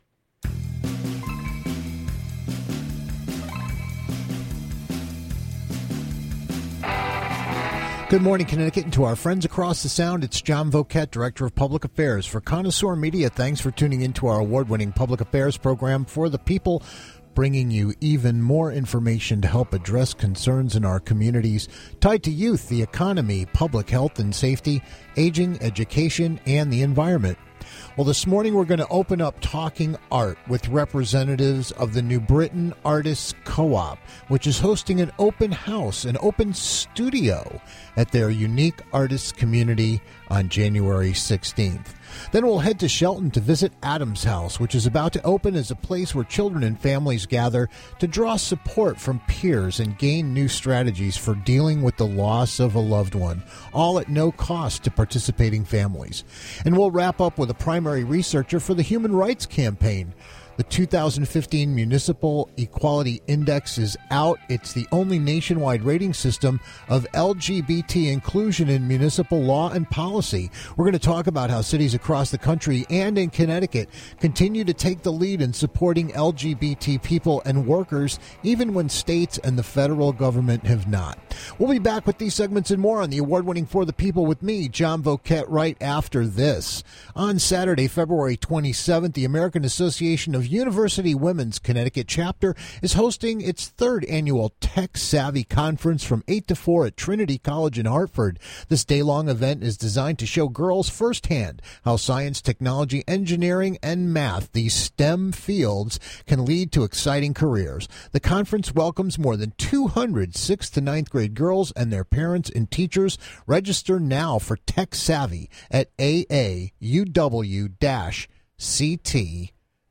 We'll take a radio tour of Adam's House, providing no cost bereavement support and unique residential recovery for families after the loss of a parent. And we'll wrap up with the Human Services Council - talking about how Connecticut is among national leaders in promoting equality among LGBT persons.